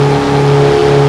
mitsuevox_low.wav